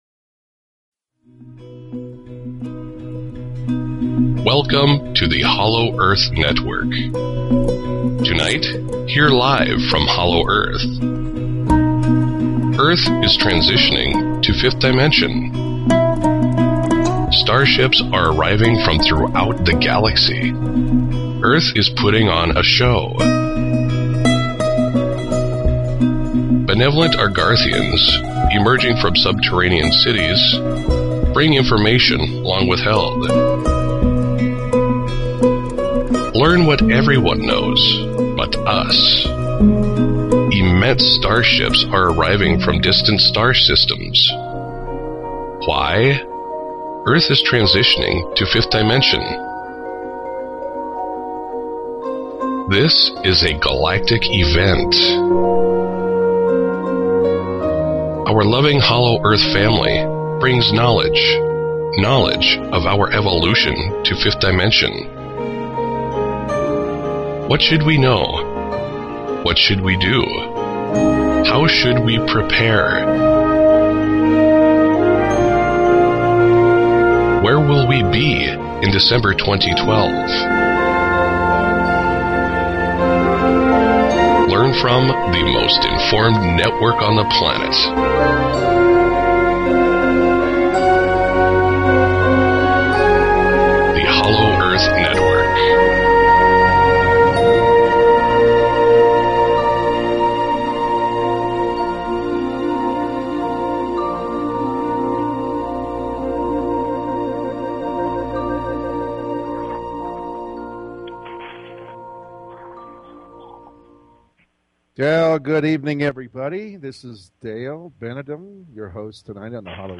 Talk Show Episode, Audio Podcast, Hollow_Earth_Network and Courtesy of BBS Radio on , show guests , about , categorized as